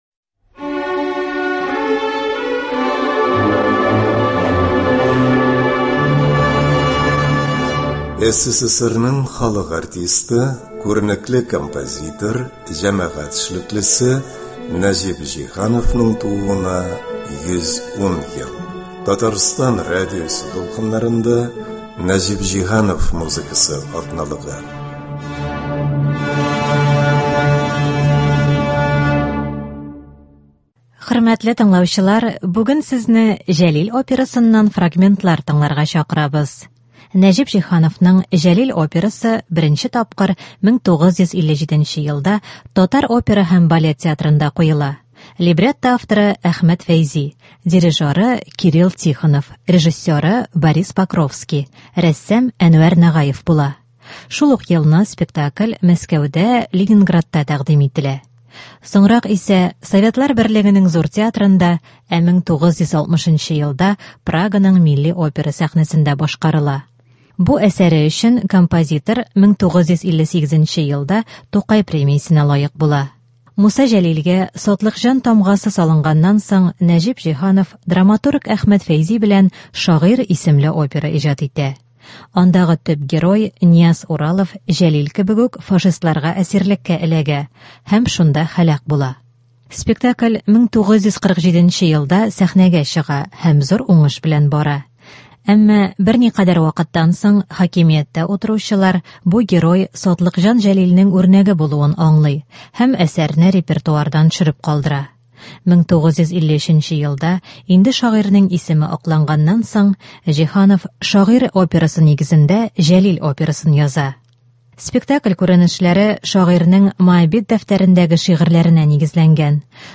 операсыннан фрагментлар.